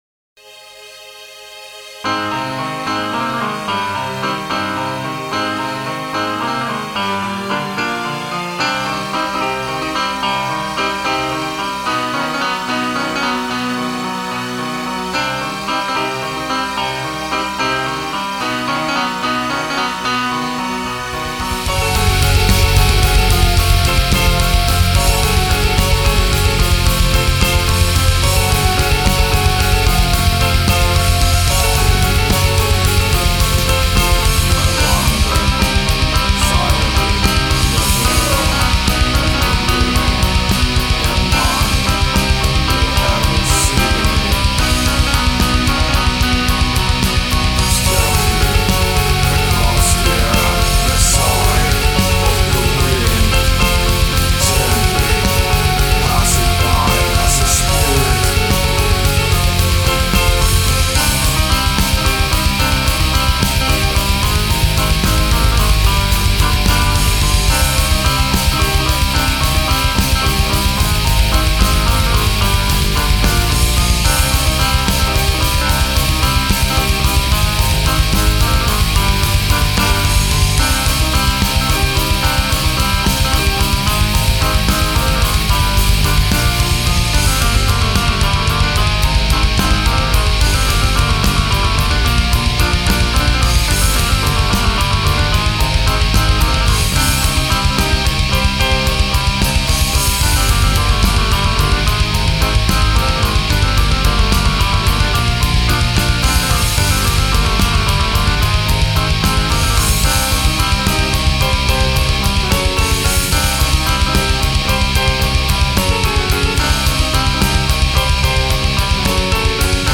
Black Metal